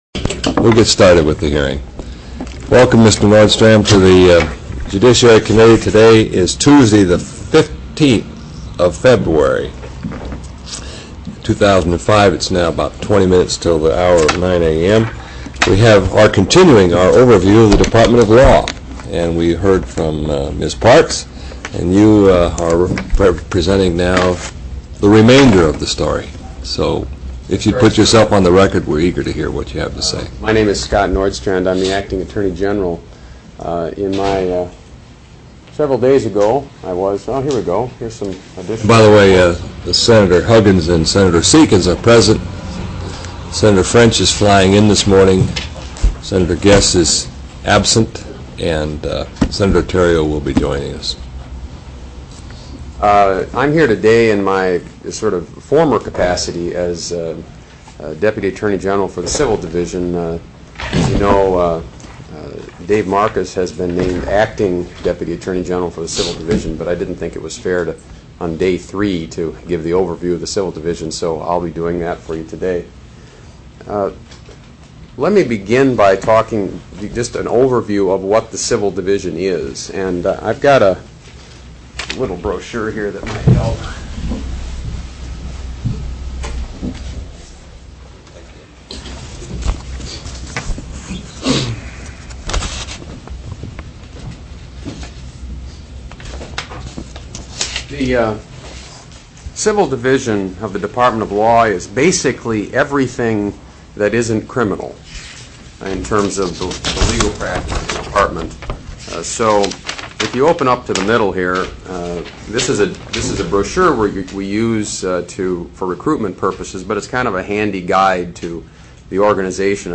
02/15/2005 08:30 AM Senate JUDICIARY